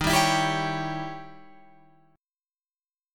D#7b9 chord